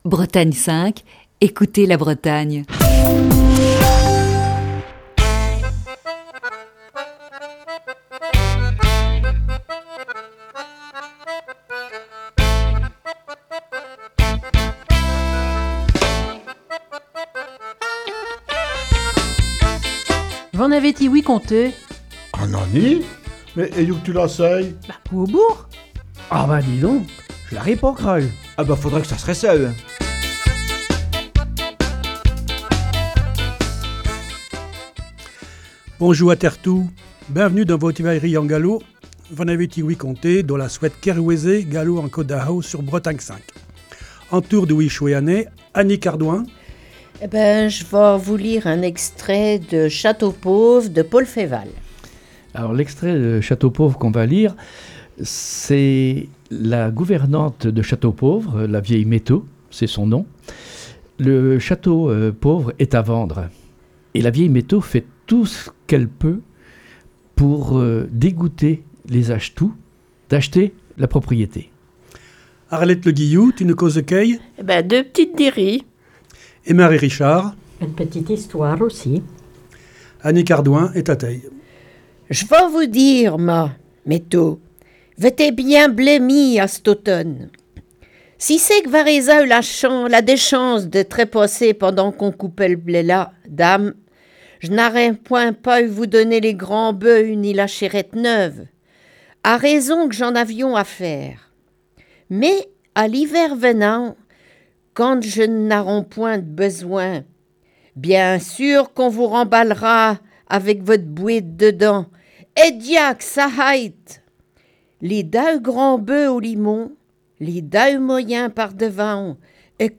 La troupe de V'en avez ti-ouï conté ? nous propose une série d'émissions basée sur "La Nuit de la Lecture", qui se déroulait le 18 janvier dernier à la Bibliothèque de Lamballe. Aujourd'hui : Paul Féval.